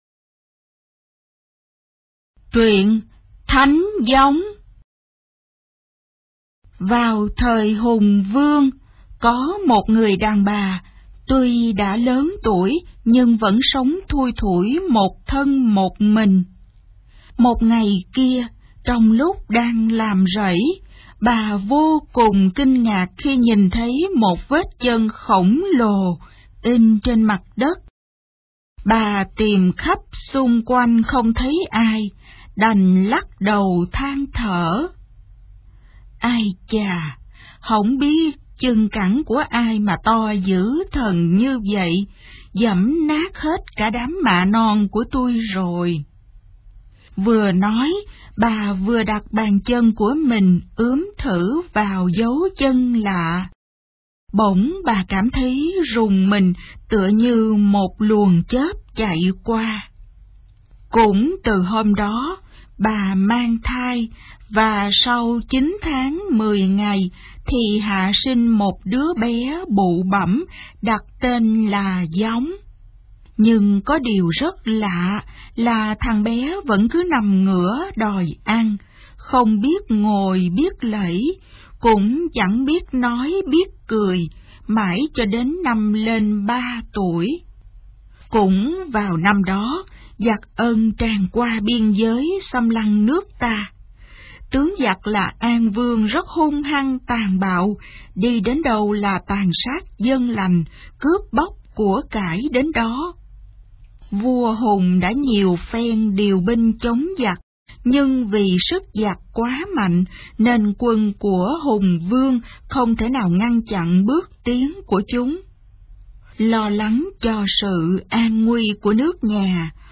Sách nói | Thánh Gióng